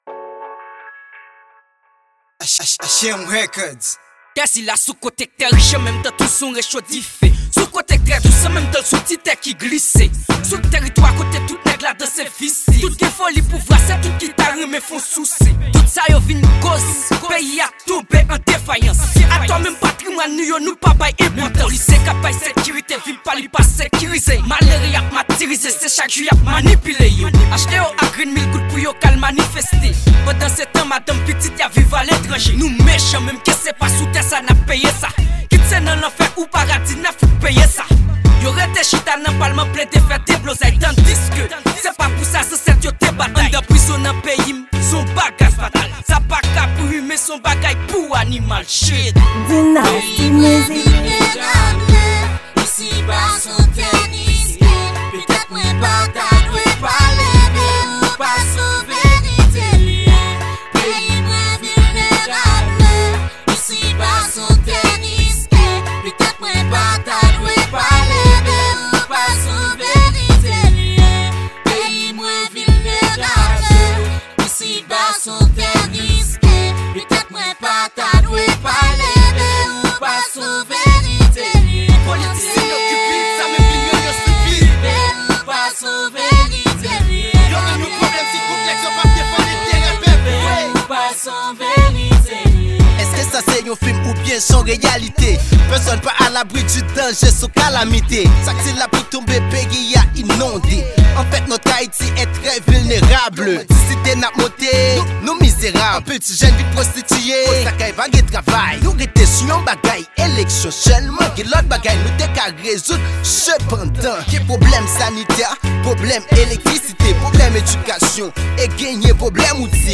Genre: Rap-Social.